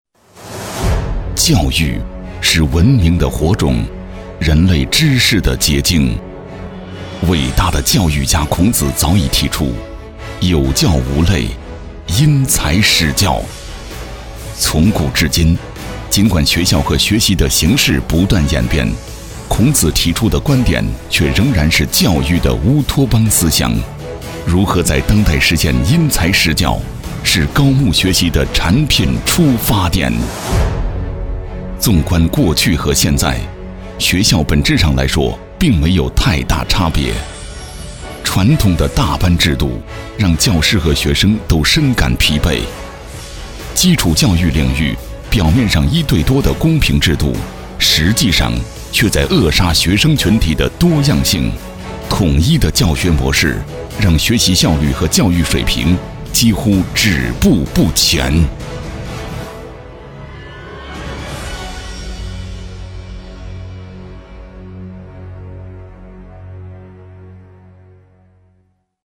20 男国191_专题_学校_高木品牌教育_激情 男国191
男国191_专题_学校_高木品牌教育_激情.mp3